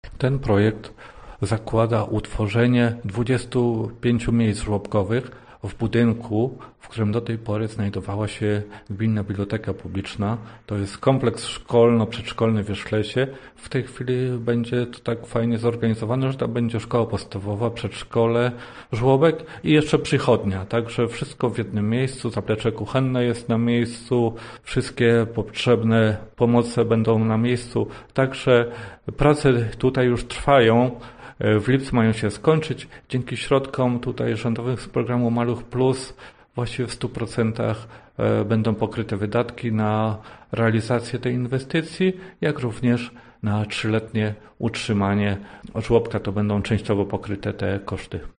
To jest kompleks szkolno-przedszkolny w Wierzchlesie – mówi Leszek Gierczyk, wójt gminy Wierzchlas.